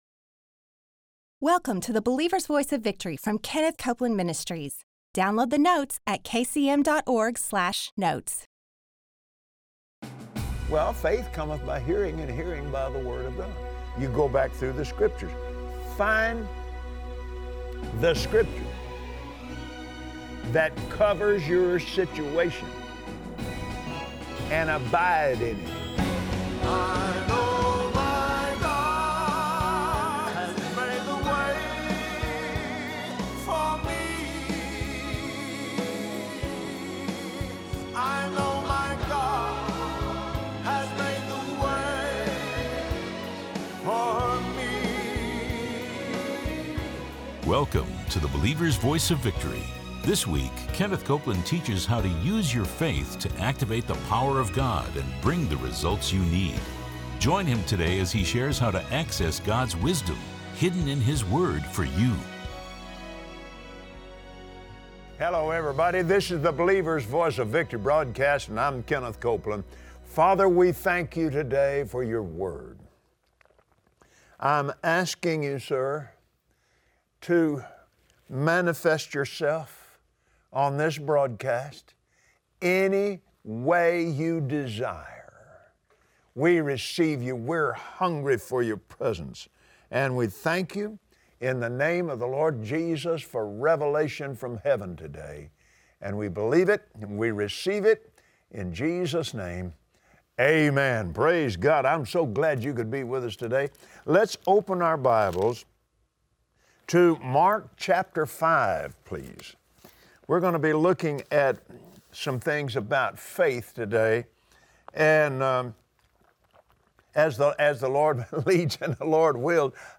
Believers Voice of Victory Audio Broadcast for Monday 05/08/2017 Do you want to see the power of God at work in your life? Watch Kenneth Copeland on Believer’s Voice of Victory as he shares how to activate the anointing power of God by activating your faith.